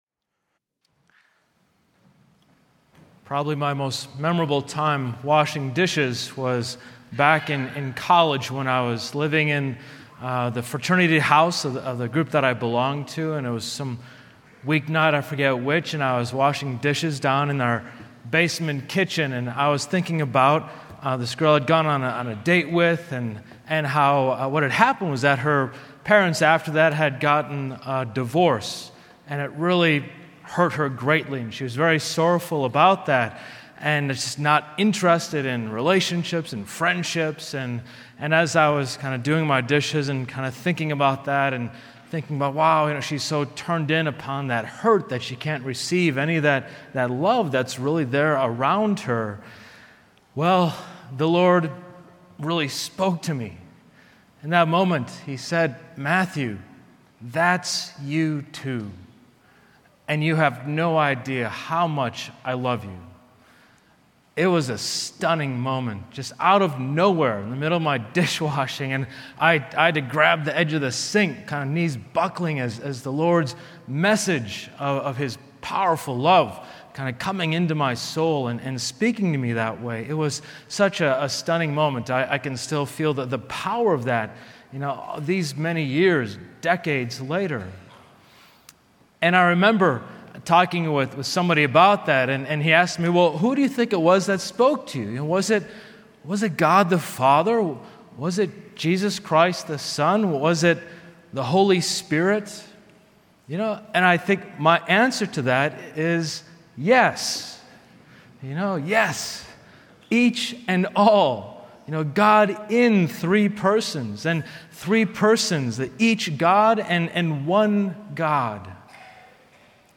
Homilies